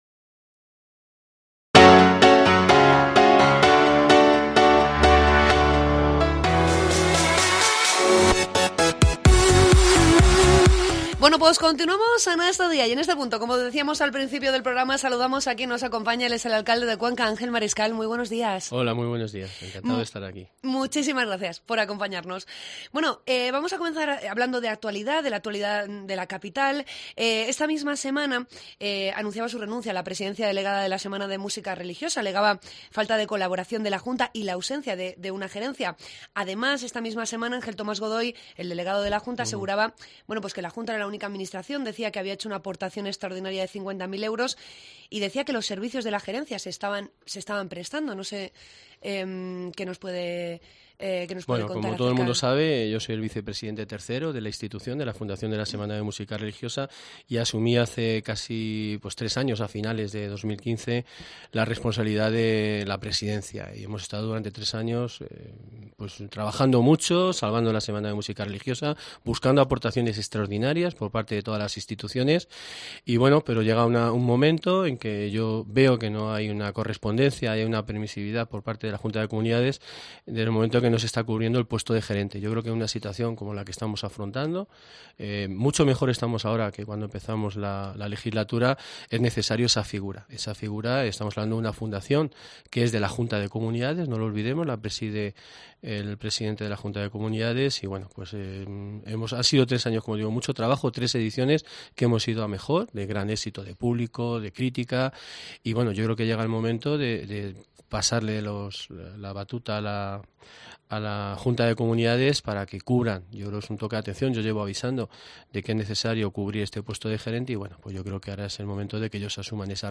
AUDIO: Entrevista con el alclade de Cuenca, Ángel Mariscal.